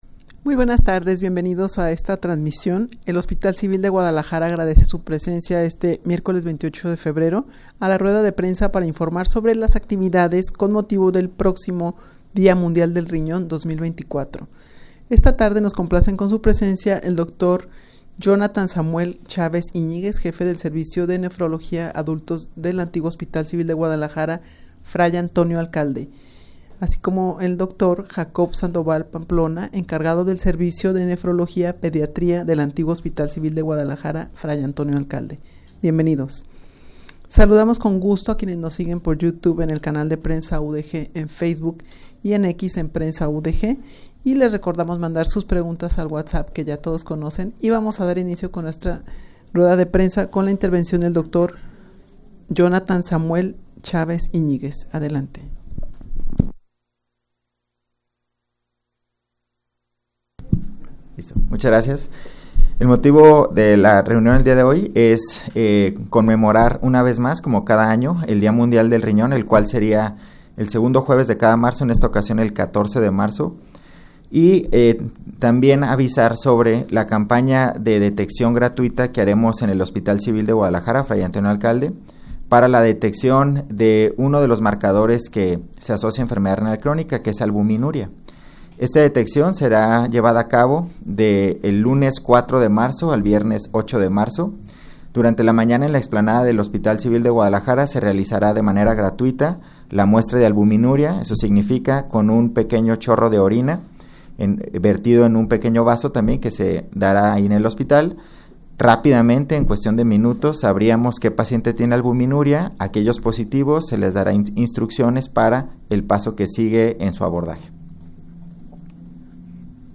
Audio de la Rueda de Prensa
rueda-de-prensa-para-informar-sobre-las-actividades-con-motivo-del-proximo-dia-mundial-del-rinon-2024.mp3